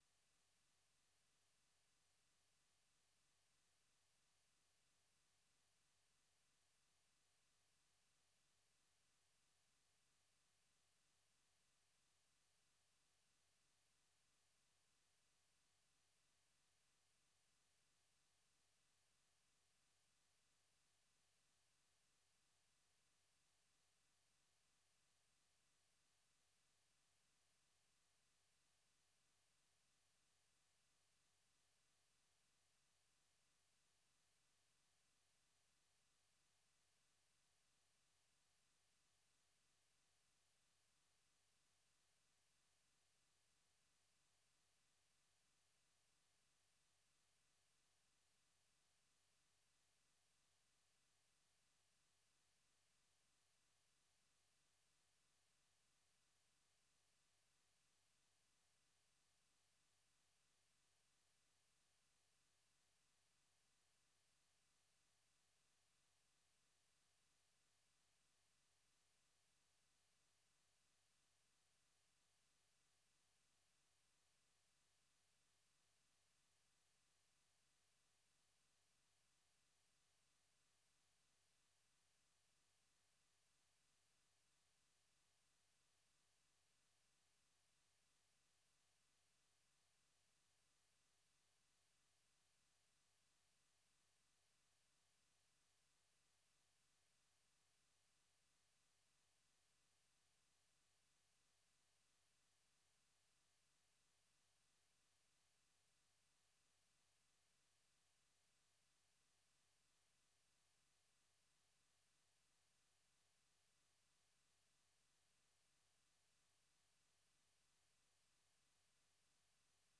Silohlelo lwezindaba esilethulela lona kusukela ngoMvulo kusiyafika ngoLwesine emsakazweni weStudio 7 ngo6:30am kusiyafika ihola lesikhombisa - 7:00am.